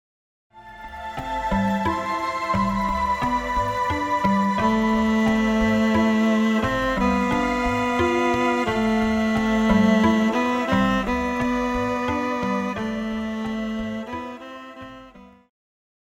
Pop
Viola
Band
Instrumental
World Music,Electronic Music
Only backing